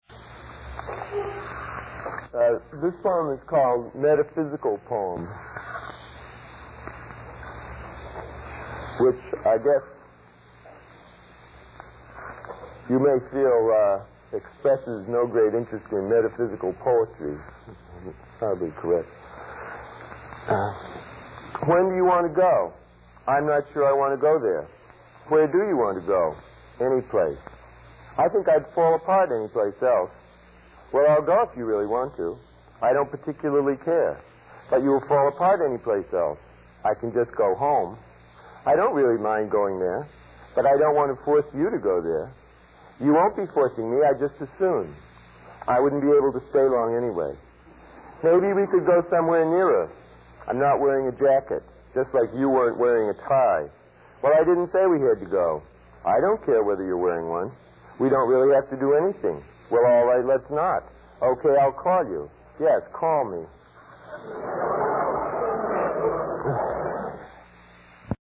Listen to Frank O’Hara read his Metaphysical Poem